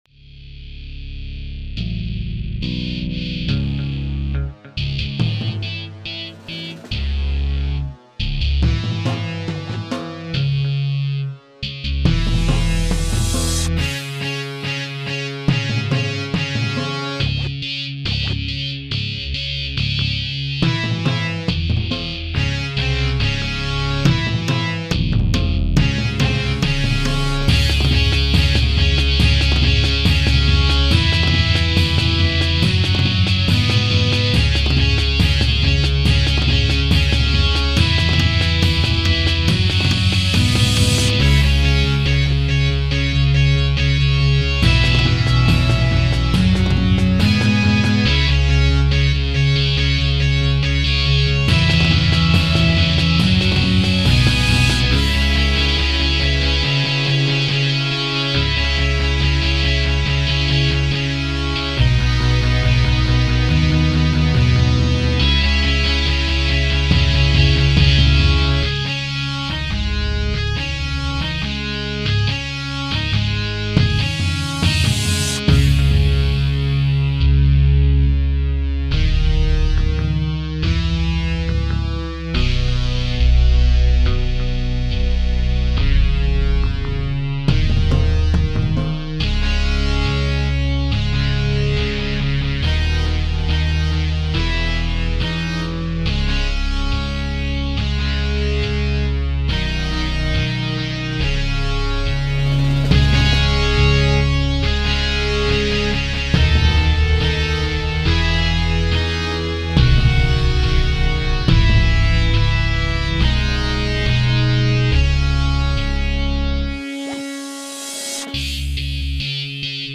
Third track made with Hell. Made using electric guitars and percussions.